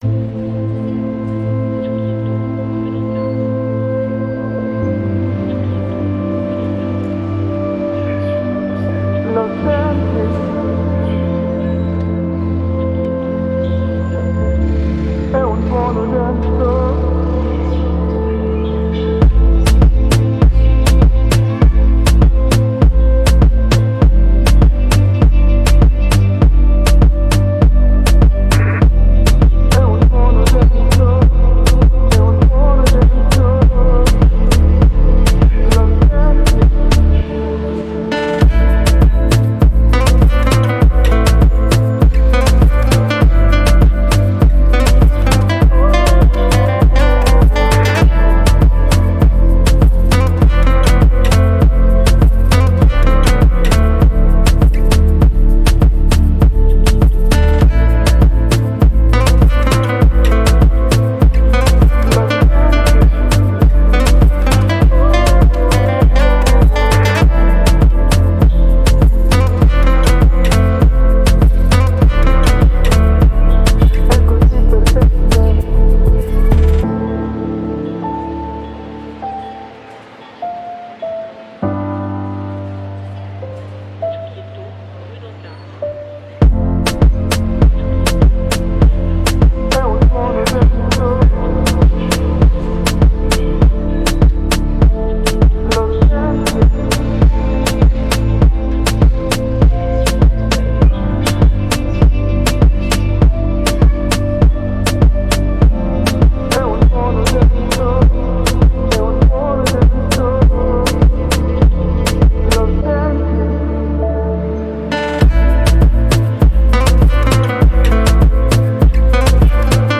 royalty free pop latin track!